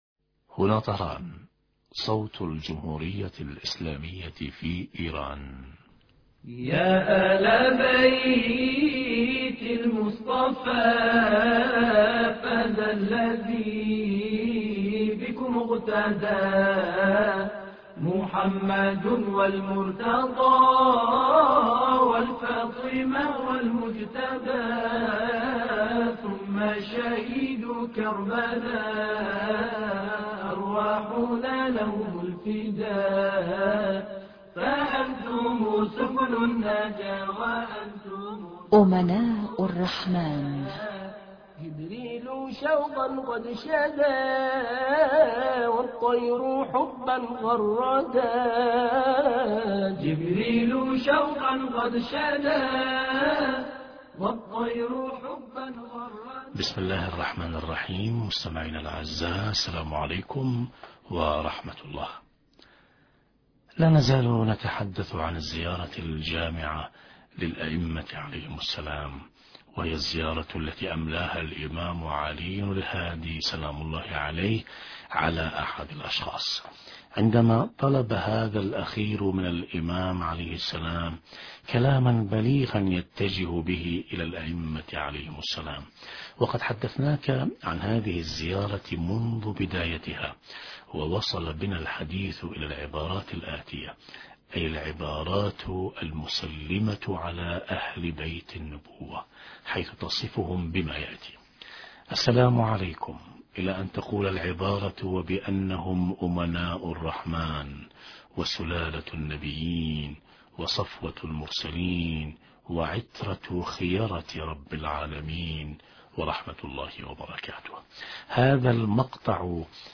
حوار
أما الآن نتابع تقديم برنامج امناء الرحمن بهذا الاتصال الهاتفي